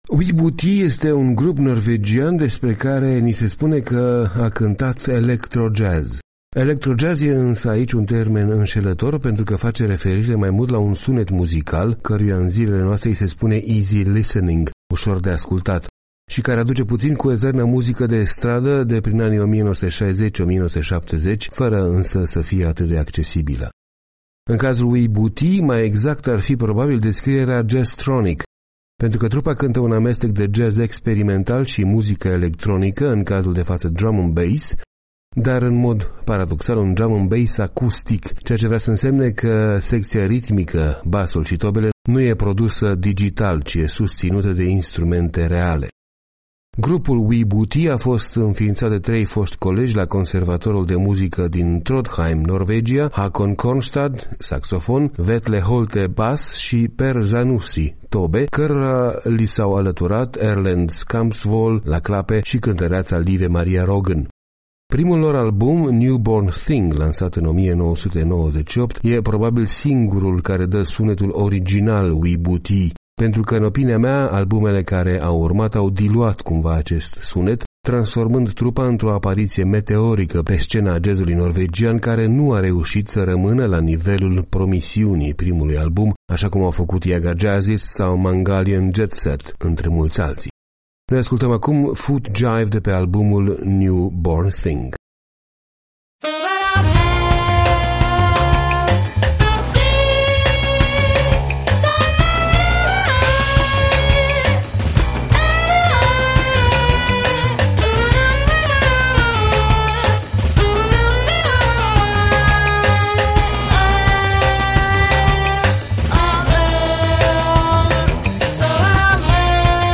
electrojazz
saxofon
la clape